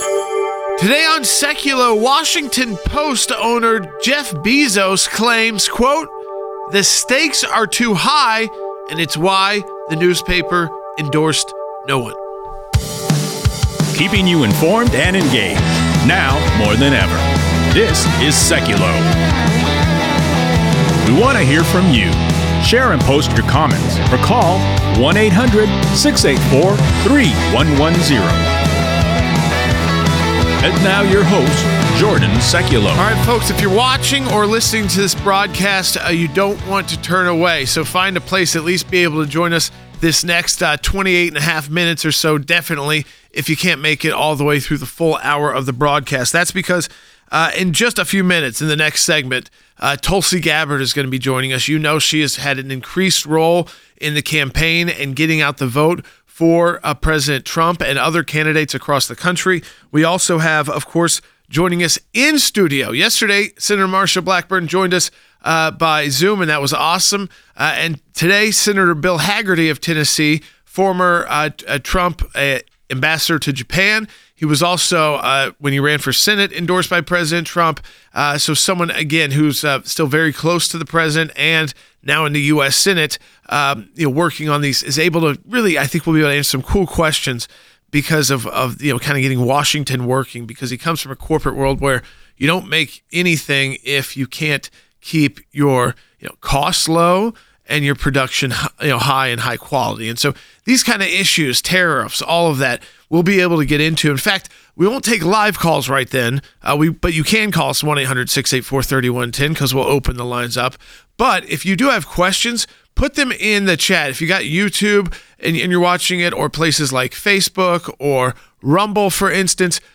Special guests include former U.S. Representative Tulsi Gabbard and Senator Bill Hagerty (in studio).